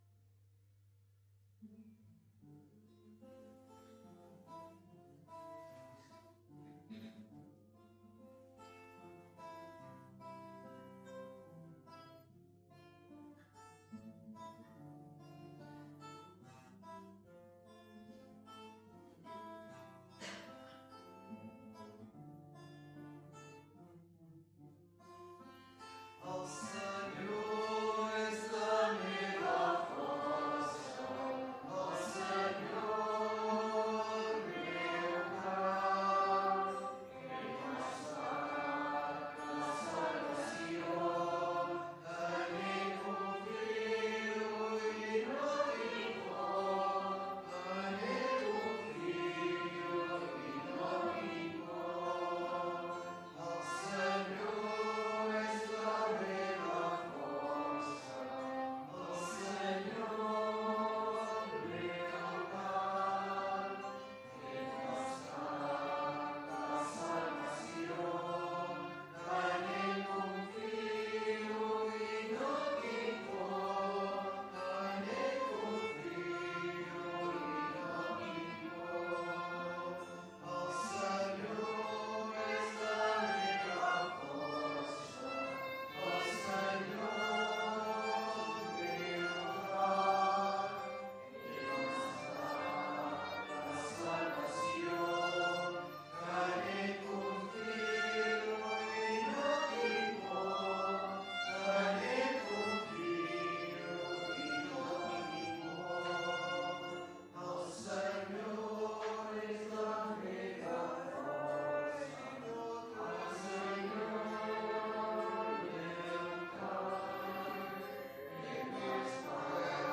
Pregària de Taizé a Mataró... des de febrer de 2001
Capella de les Concepcionistes de Sant Josep - Diumenge 25 de novembre de 2018